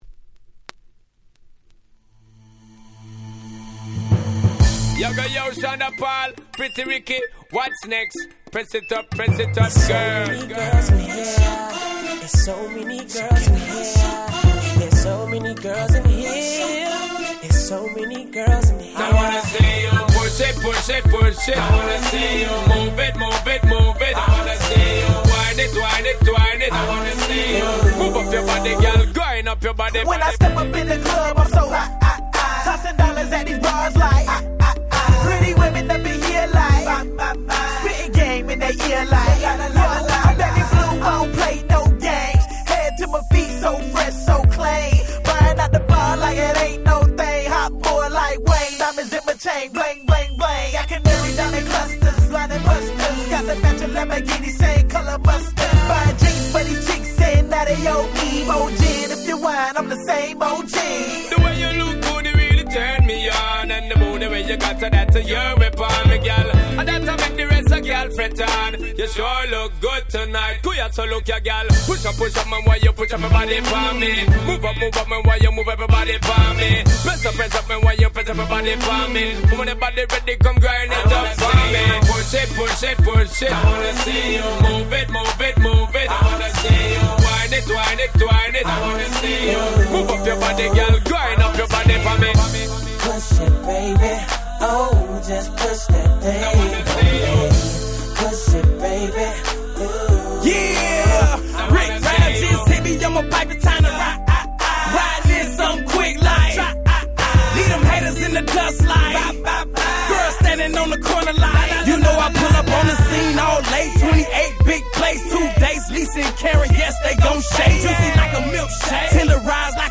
07' Smash Hit R&B !!
レゲエ キャッチー系 00's